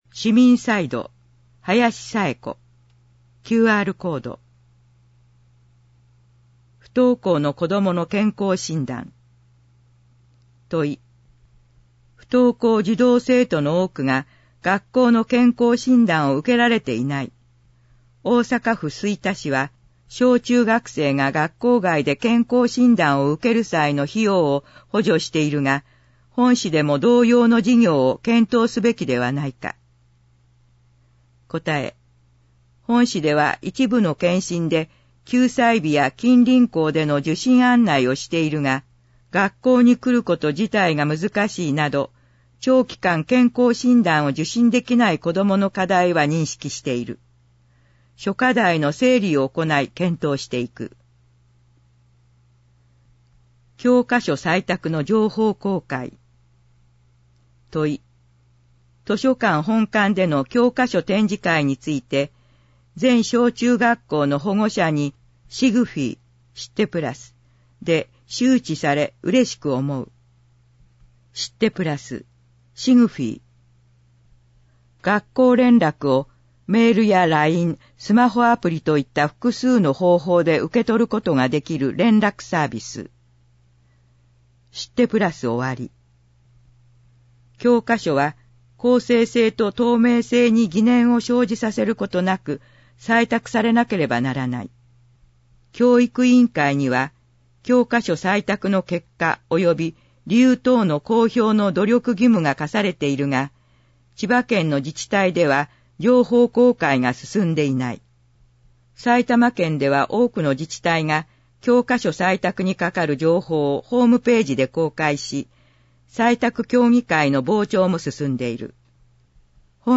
• かしわ市議会だよりの内容を音声で収録した「かしわ市議会だより音訳版」を発行しています。
• 発行は、柏市朗読奉仕サークル（外部サイトへリンク）にご協力いただき、毎号行っています。